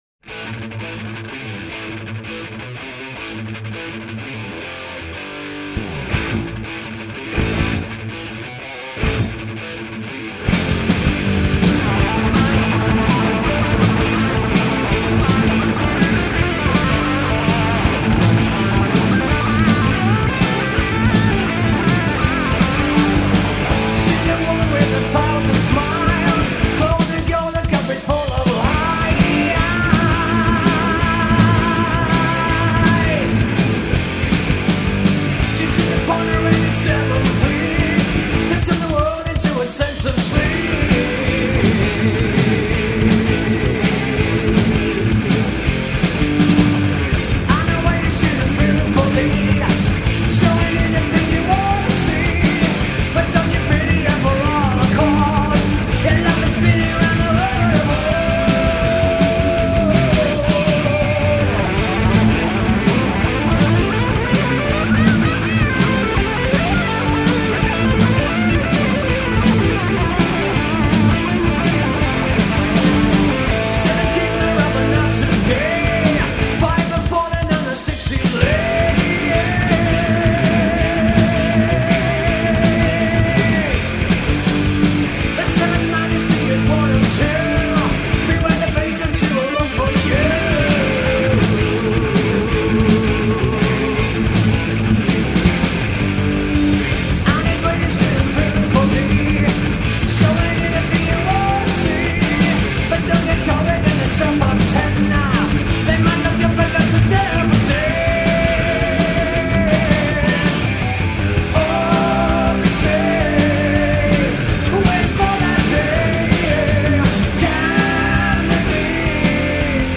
dance/electronic
Heavy metal